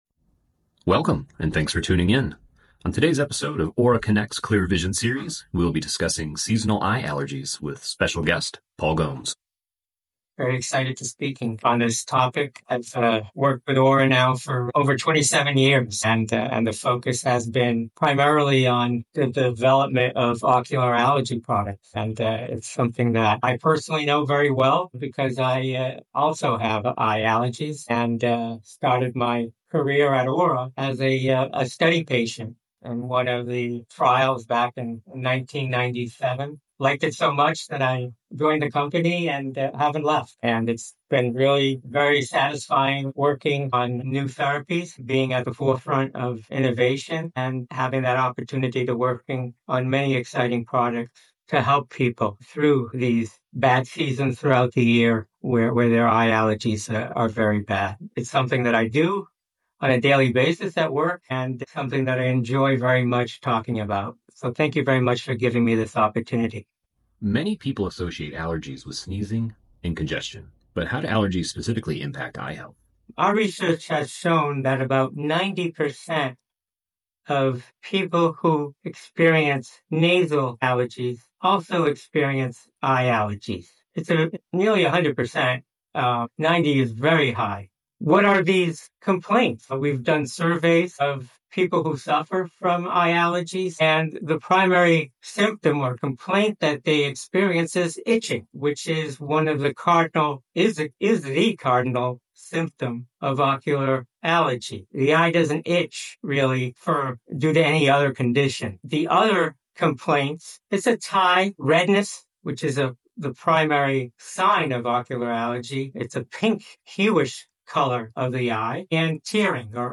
In our enlightening conversation